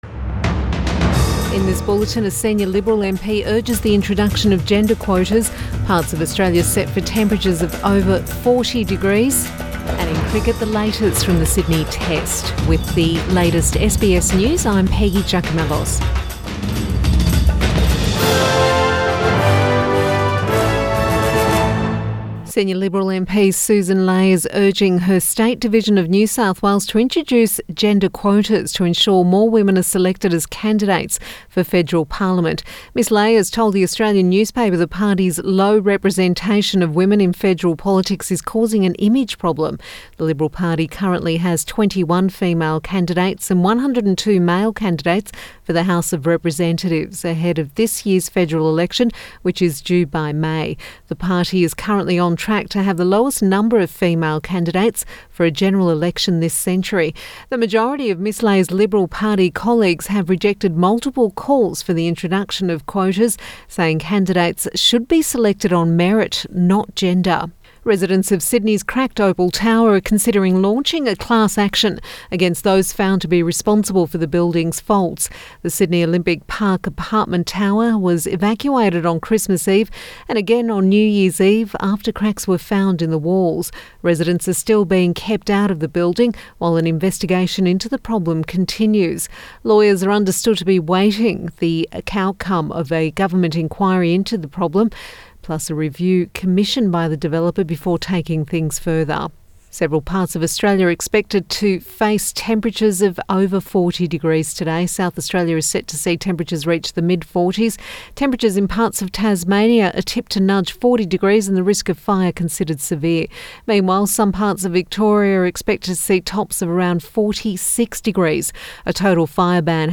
Midday Bulletin Jan 4